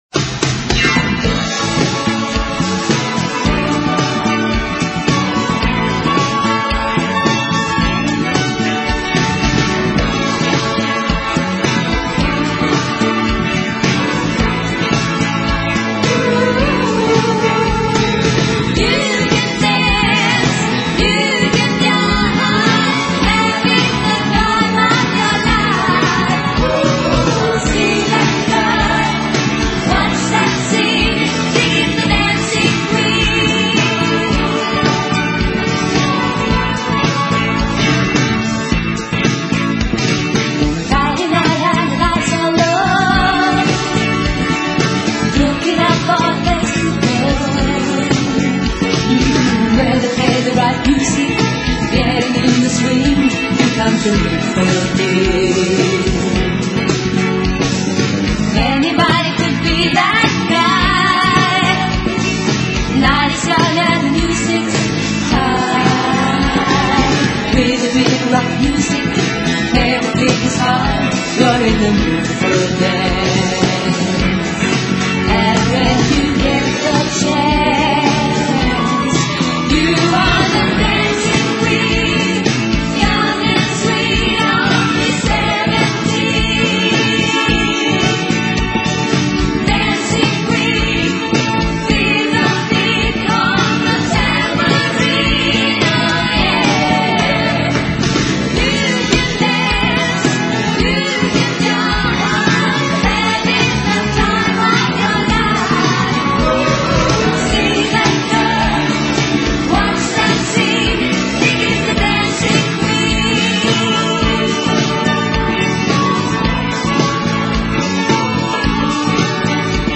音乐风格： 流行|流行/摇滚|(Pop/Rock)